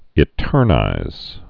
(ĭ-tûrnīz)